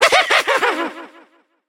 evil_gene_vo_01.ogg